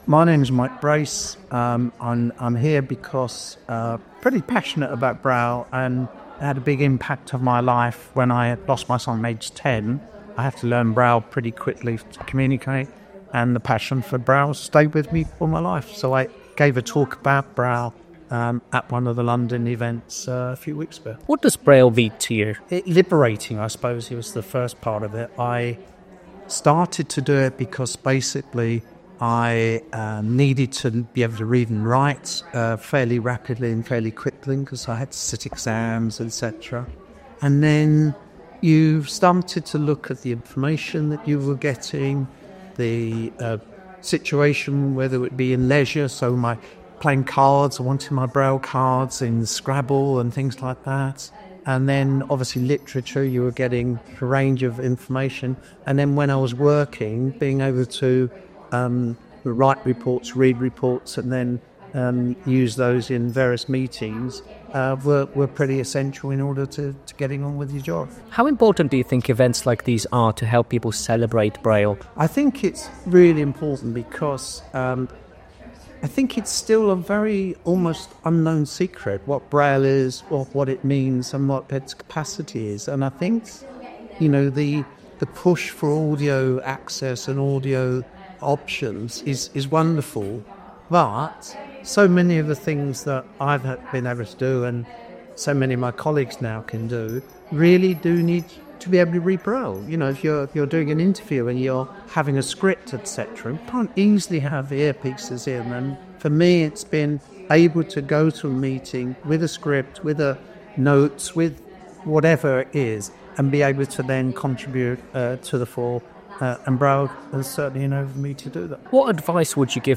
Charity RNIB and the British Library hosted an event to celebrate the bicentenary of the invention of braille, the system of raised dots that allows blind and partially sighted people to read with their fingers.